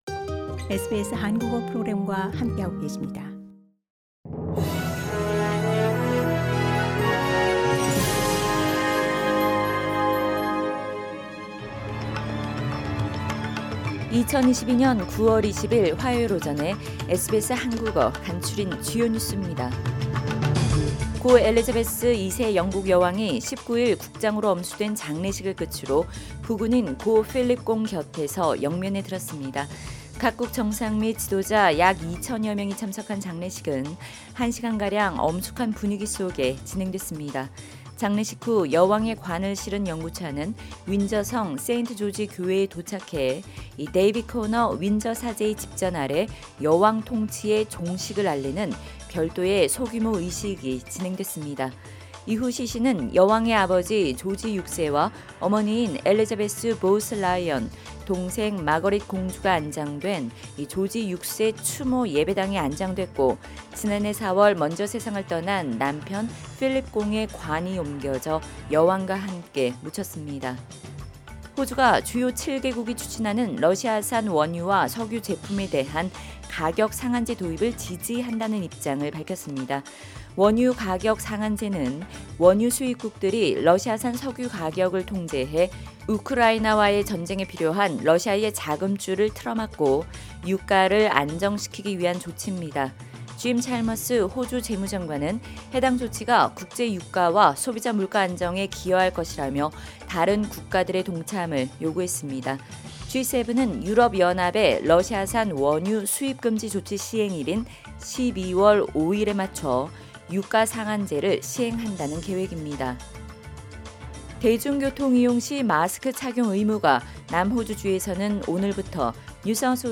2022년 9월 20일 화요일 아침 SBS 한국어 간추린 주요 뉴스입니다.